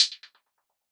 kits/OZ/Closed Hats/Hihat Roll 3.wav at main
Hihat Roll 3.wav